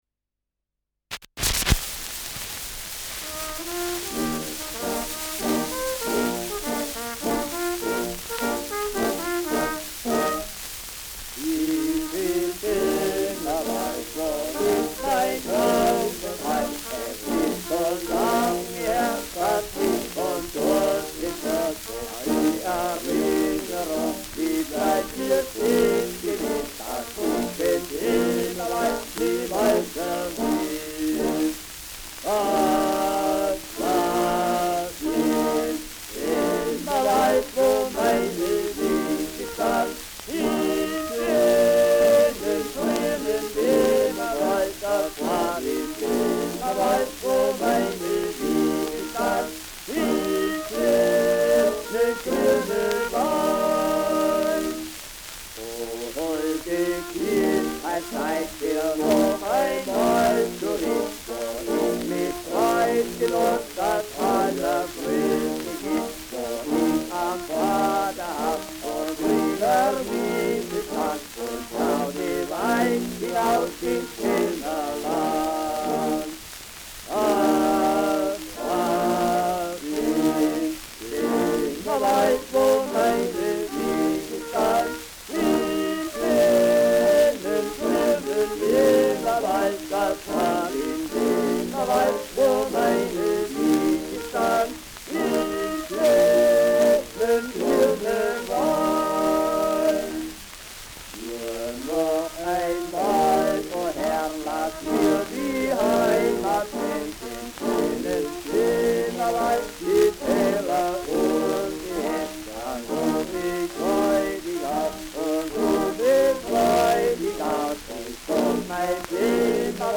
Schellackplatte
Starkes Grundrauschen : Verzerrt an lauteren Stellen : Vereinzelt leichtes Knacken